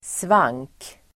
Ladda ner uttalet
svank substantiv, inward curve of the lower part of the back Uttal: [svang:k] Böjningar: svanken, svankar Definition: inböjning i ryggradens nedre del Sammansättningar: svank|ryggig (sway-backed, suffering from lordosis)